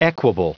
Prononciation du mot equable en anglais (fichier audio)
Prononciation du mot : equable
equable.wav